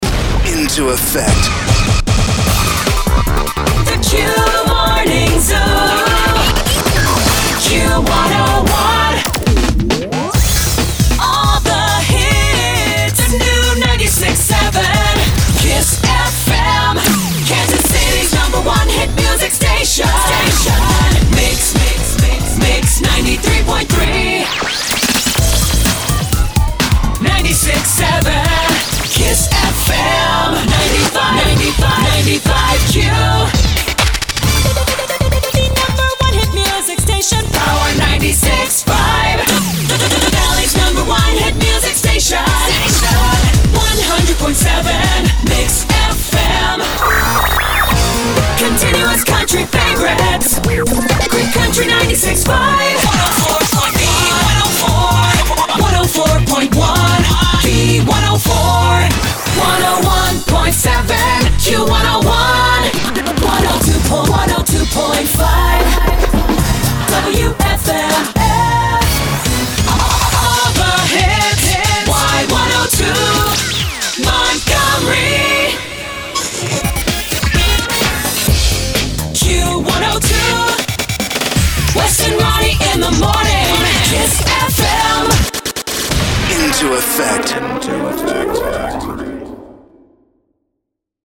here’s a new montage of resings